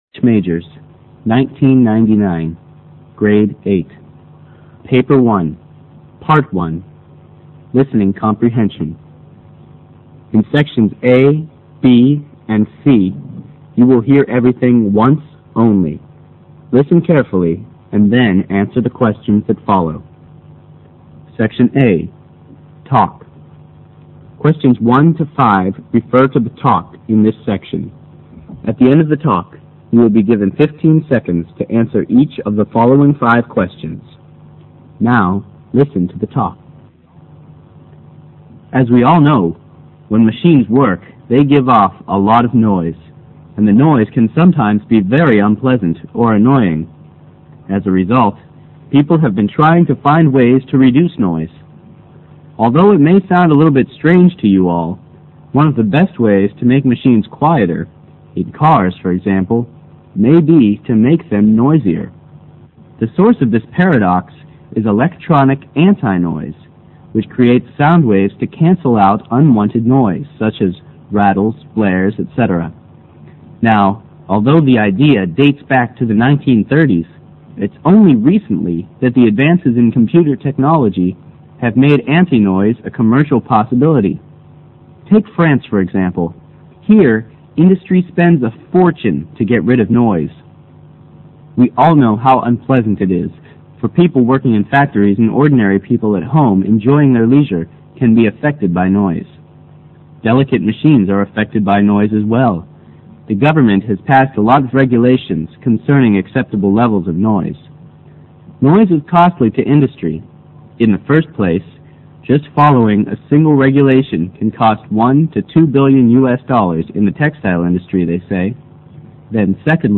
PART �� LISTENING COMPREHENSION��